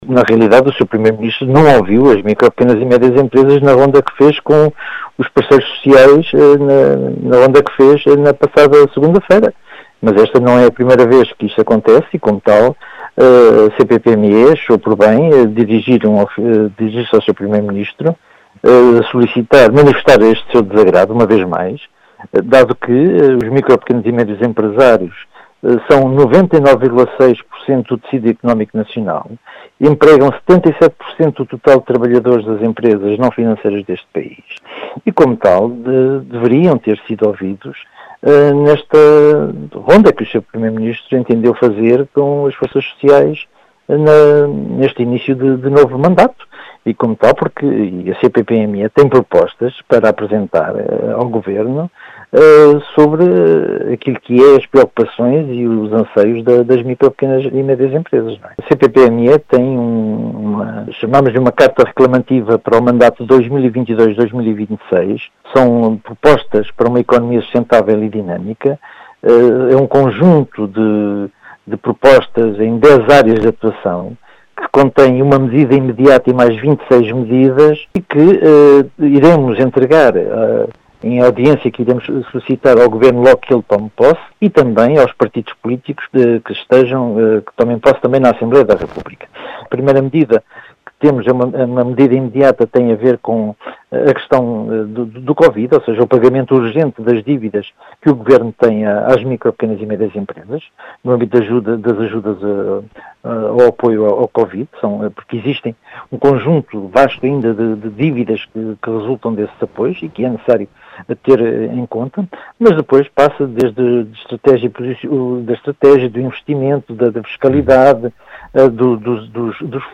As explicações foram deixadas aos microfones da Rádio Vidigueira